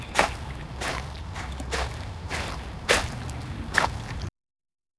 gentle-water-ripples-soft-u3lzkhqb.wav